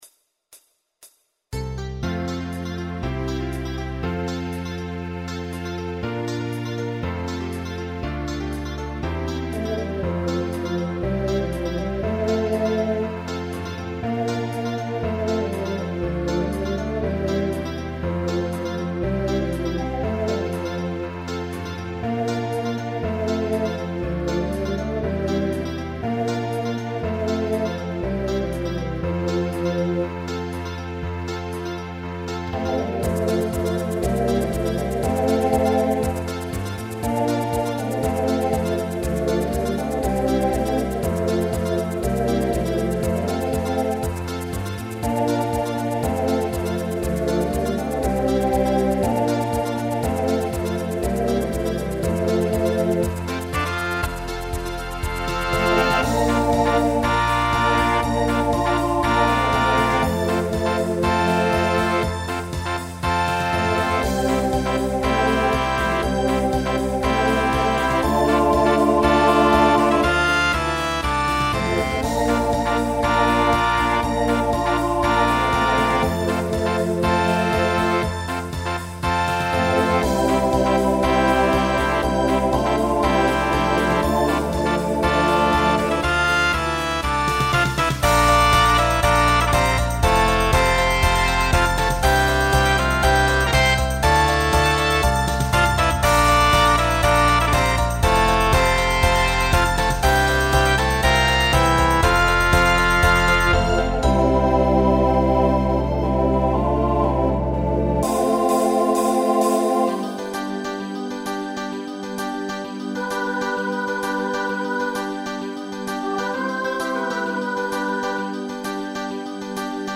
TTB/SSA
Voicing Mixed Instrumental combo Genre Pop/Dance